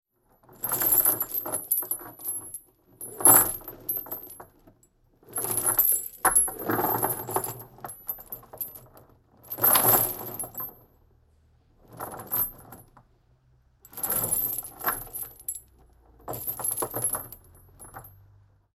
На этой странице собраны звуки кандалов: звон цепей, скрежет металла, тяжелые шаги в оковах.
Звук кандалов когда человек двигается сидя и цепь шумит